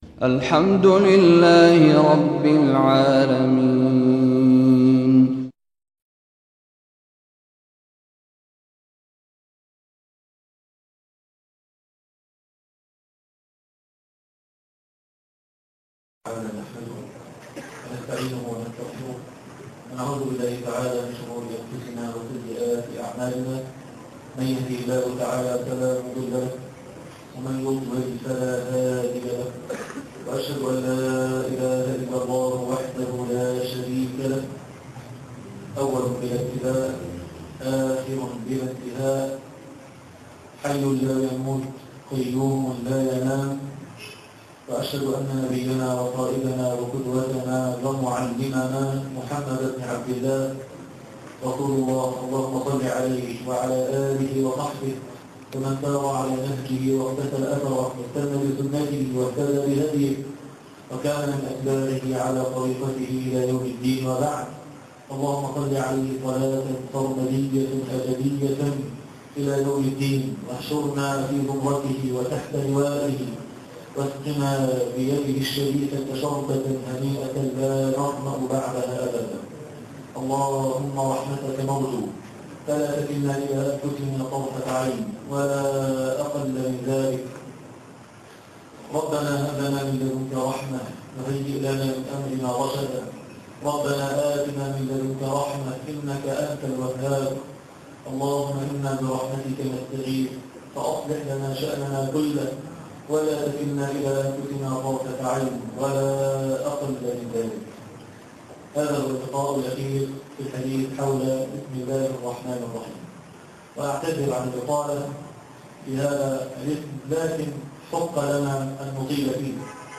تفاصيل المادة عنوان المادة إنه ربي - الدرس الثاني عشر تاريخ التحميل الأثنين 29 سبتمبر 2025 مـ حجم المادة 38.08 ميجا بايت عدد الزيارات 194 زيارة عدد مرات الحفظ 71 مرة إستماع المادة حفظ المادة اضف تعليقك أرسل لصديق